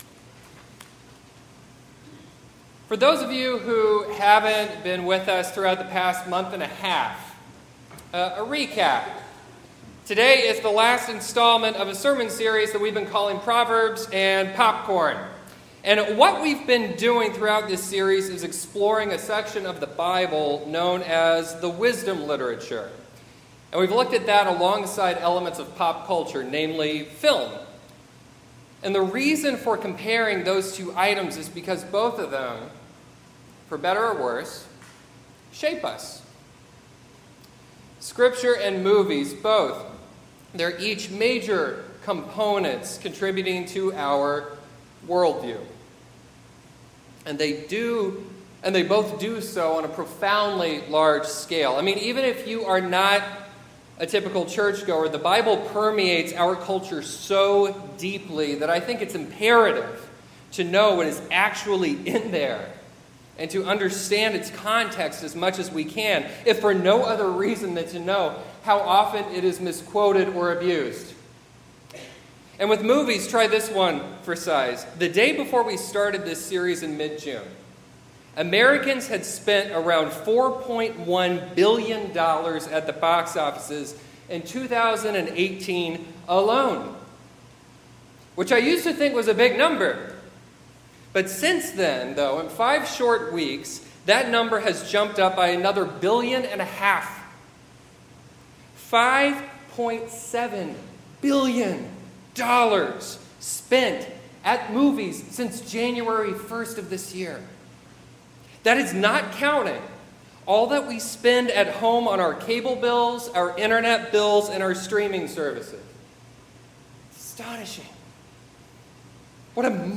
Reading Ecclesiastes 2:24-25